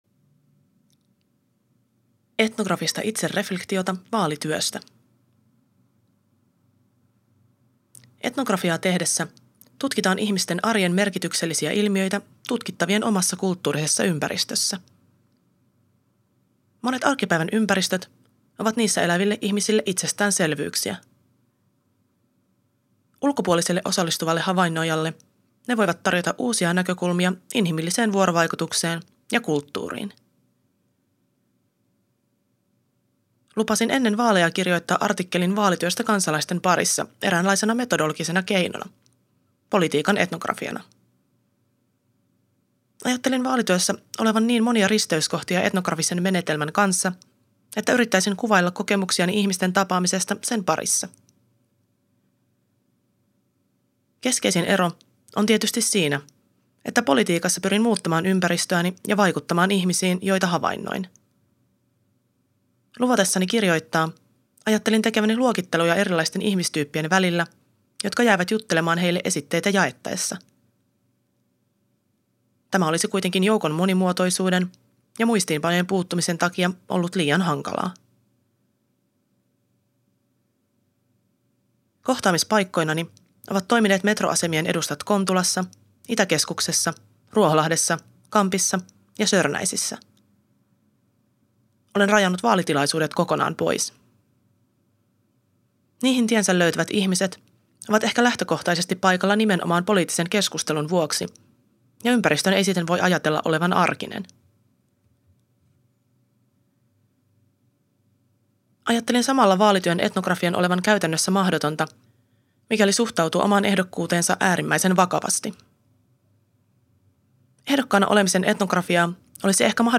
Podcast-lukija